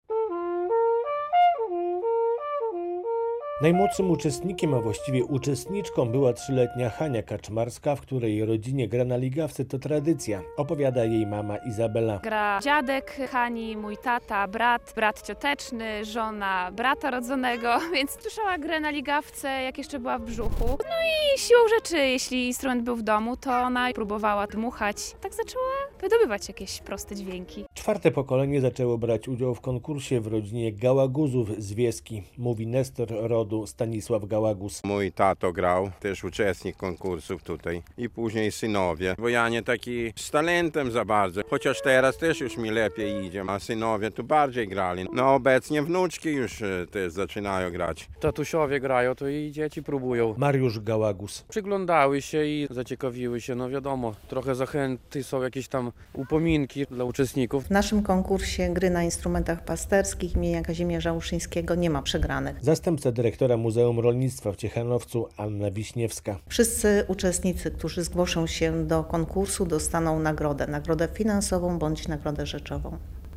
Dźwięki ligawek i trombit będzie słychać w Ciechanowcu - trwa 42. Konkurs Gry na Instrumentach Pasterskich
42. Konkurs Gry na Instrumentach Pasterskich - relacja